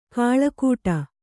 ♪ kāḷakūṭa